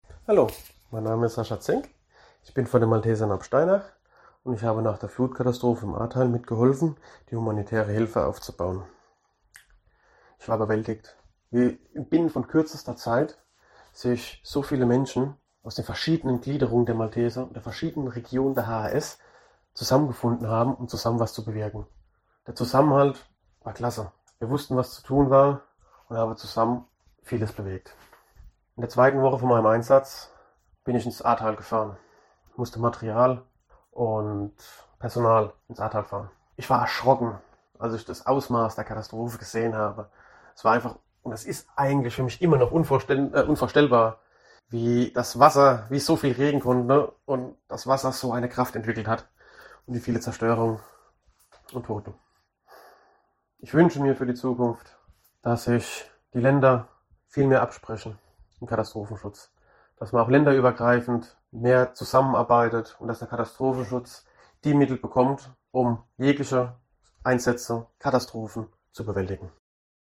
Sechs von ihnen teilen ihre persönlichen Eindrücke mit uns, sprechen ungefiltert und offen von ihren Erfahrungen, die sie bis heute begleiten.